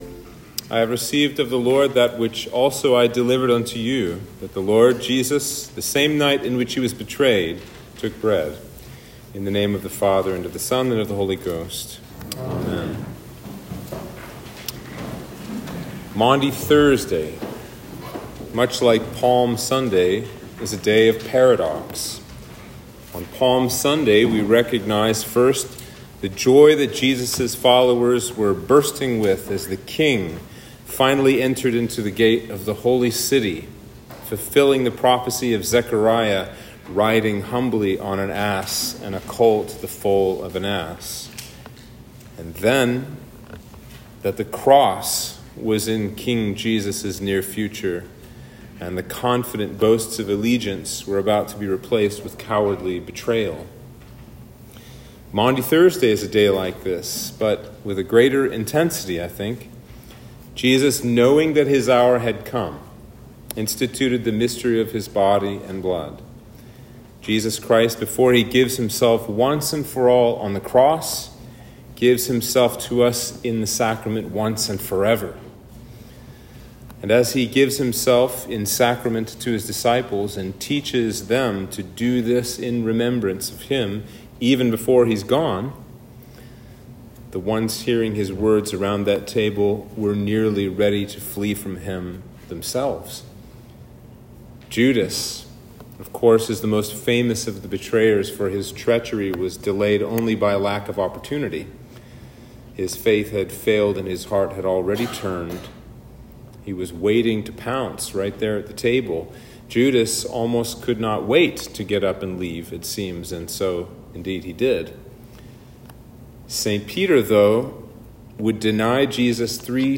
Sermon for Maundy Thursday